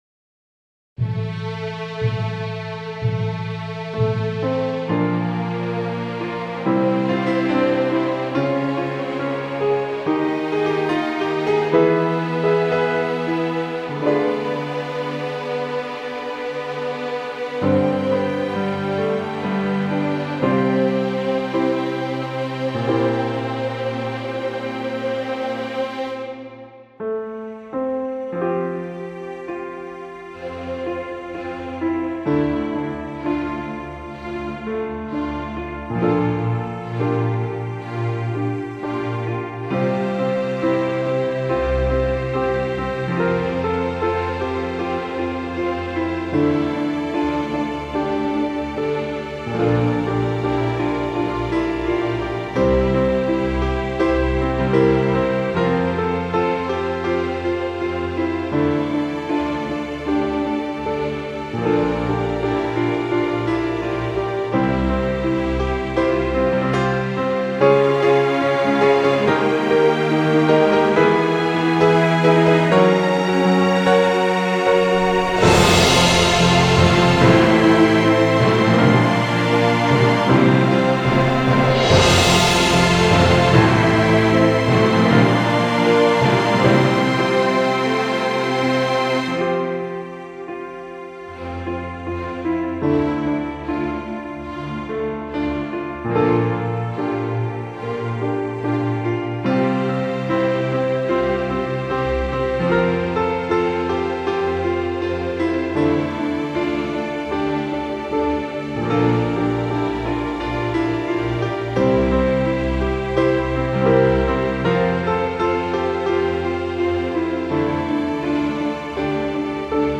So this track is just the accompaniment without vocals.
Using EWQL vsts including Hollywood strings gold, Symphonic Orchestra on the timpani, EWQL Symphonic Choirs.
So it's all EWQL software, and spaces reverb on each track.
Didn't use any compression or additional eq.